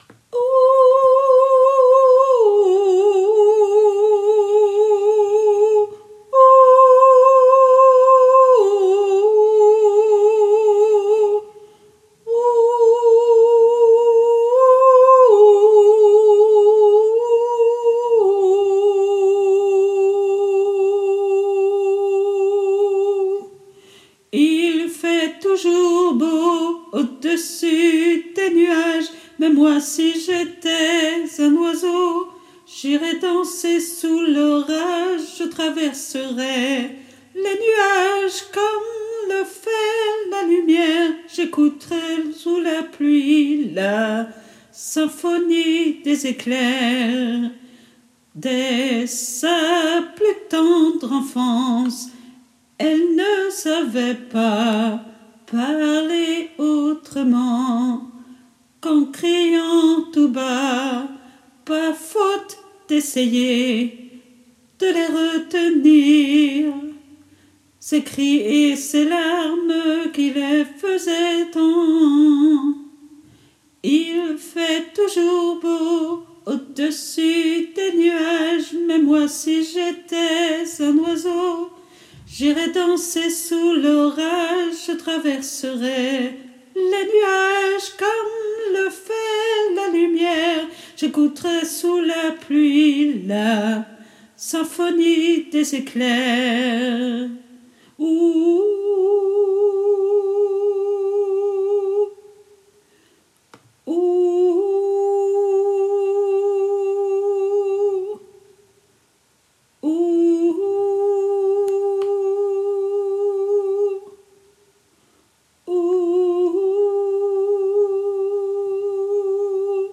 MP3 versions chantées (les audios peuvent être téléchargés)
Voix 1 (soprano et ténor)